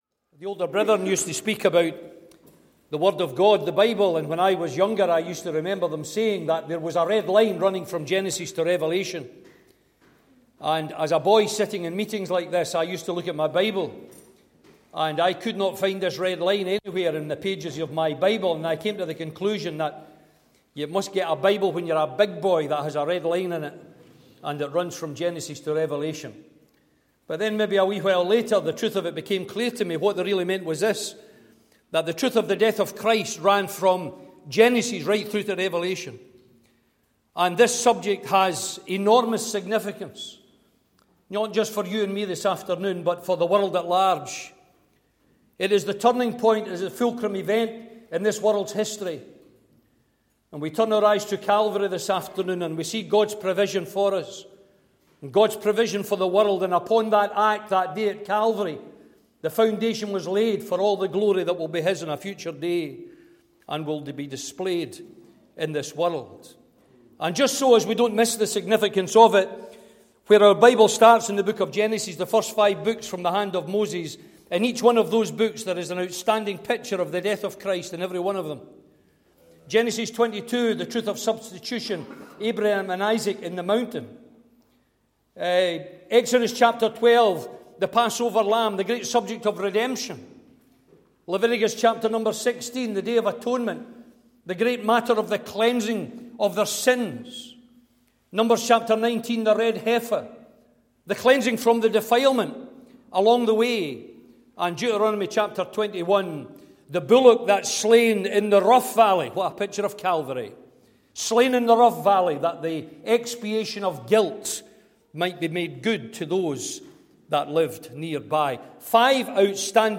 EASTER CONF 2025